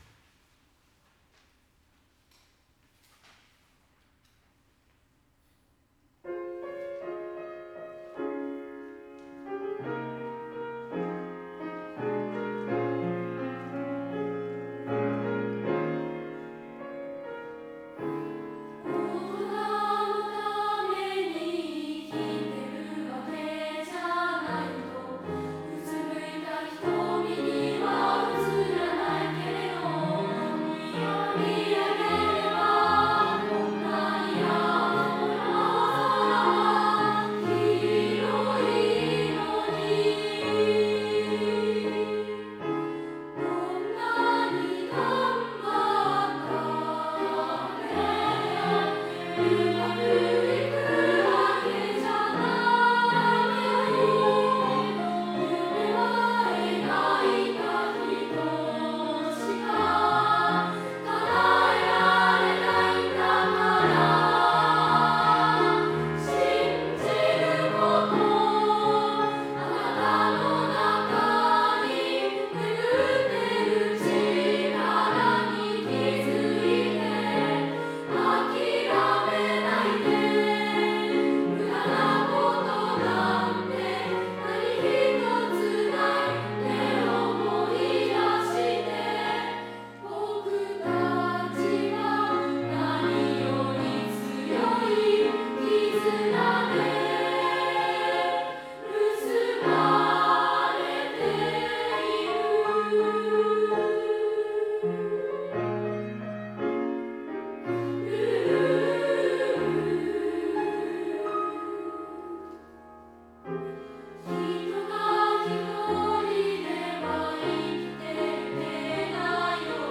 明日は上都賀地区学校音楽祭があります。
合唱部25人は夏休みからこの日に向けて練習に取り組んできました。
鹿沼市立西中学校　合唱「結」.wav
学校でとった音源をアップしてみました。